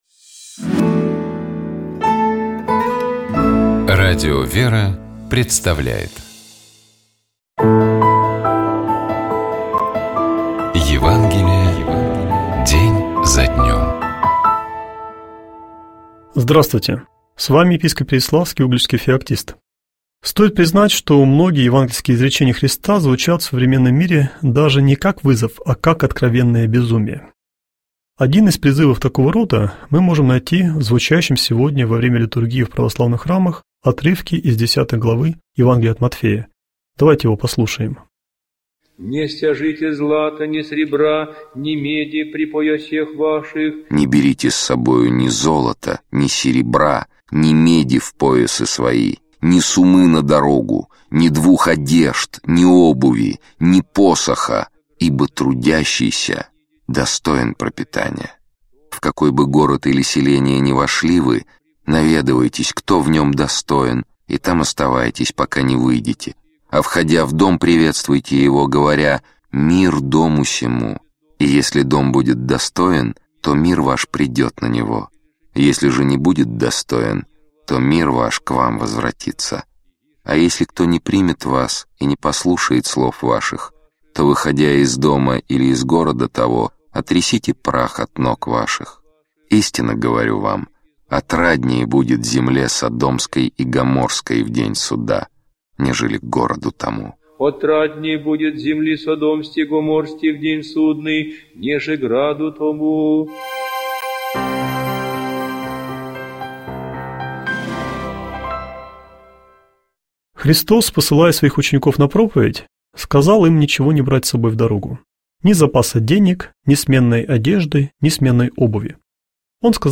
епископ Феоктист ИгумновЧитает и комментирует епископ Переславский и Угличский Феоктист